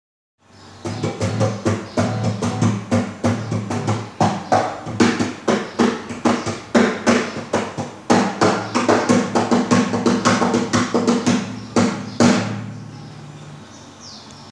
Batucada Com Violão Batucada com o viol??o
batucada_com_o_viol??o.mp3